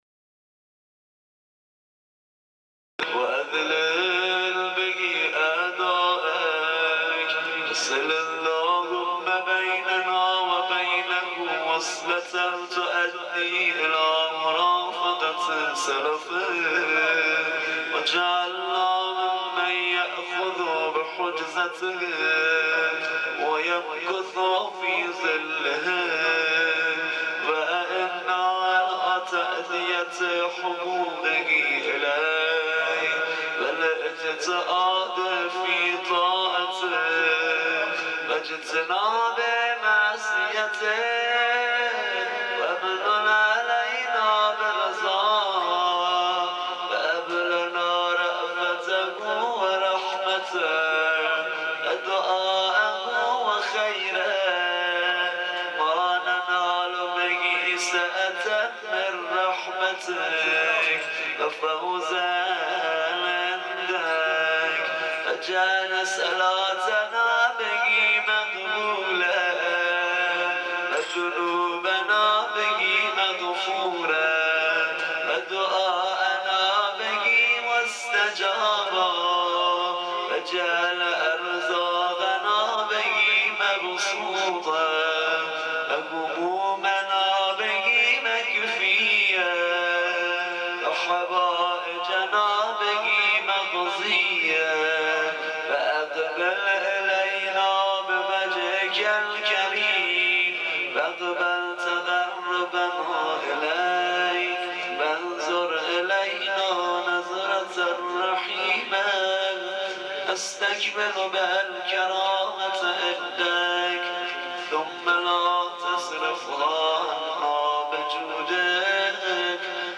۵۸ -جلسه دعای ندبه سال ۱۳۸۴ شمسی در مشهد (سخنرانی